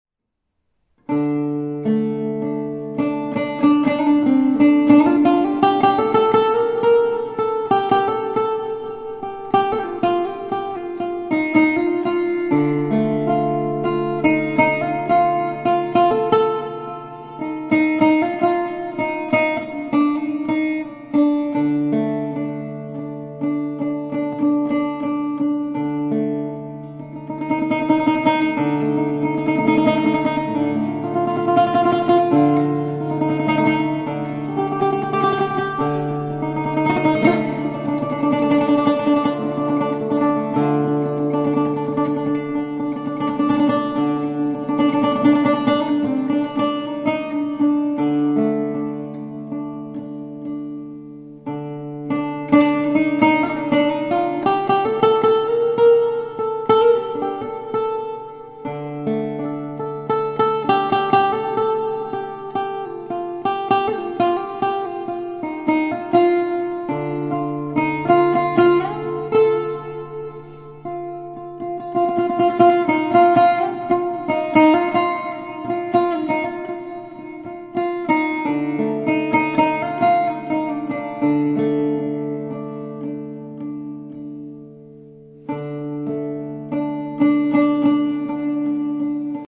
mandora/lute guitar
I use a Turkish risha (plectrum).